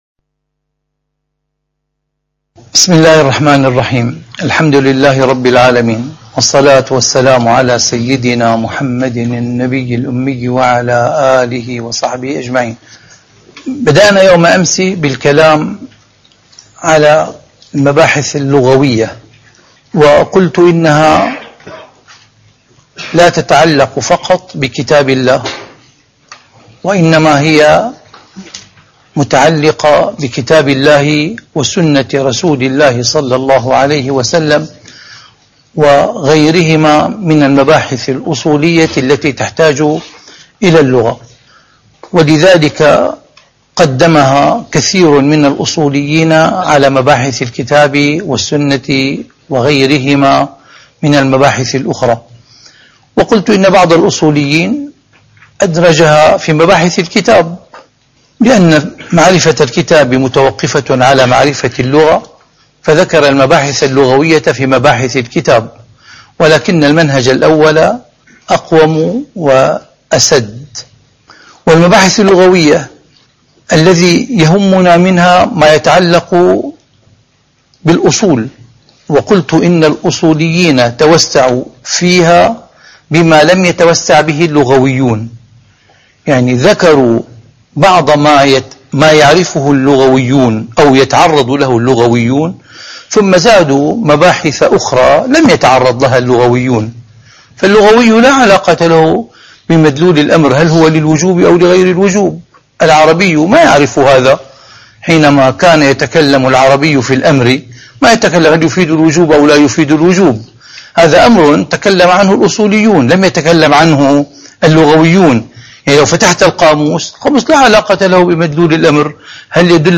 أصول الفقه / الدرس الرابع:المباحث الأصولية والمباحث المتعلقة بالألفاظ (2)